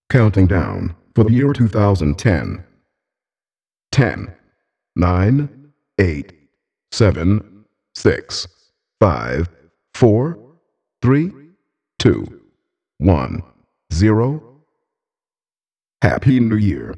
DJ House Container » New Years Eve 2010 Countdown Acapella Sample
Two with a male voice and one with a female voice. They use computer generated vocals and are processed in Ableton Live to make them sound fatter.
The countdown starts with an intro and outro voice. The actual countdown from 10 to 0 is seconds based.